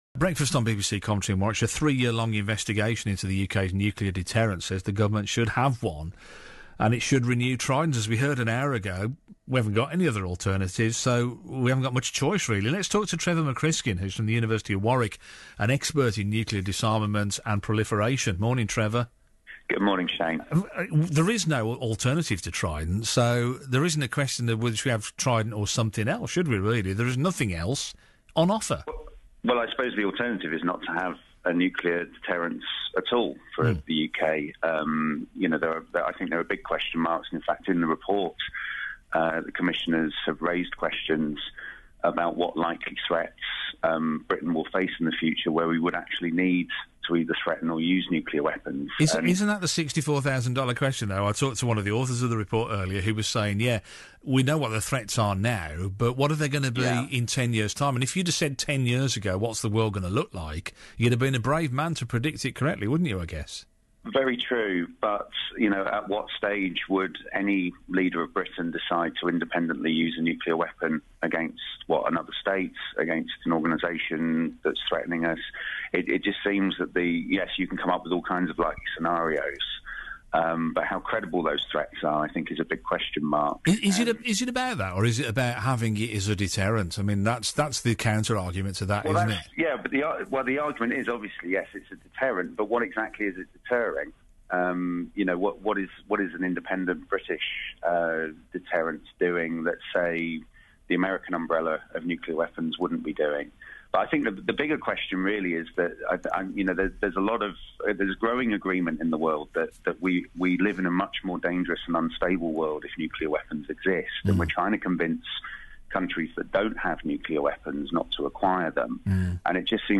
On Tuesday morning, ahead of the formal release of the BASIC Trident Commission Report in the Houses of Parliament,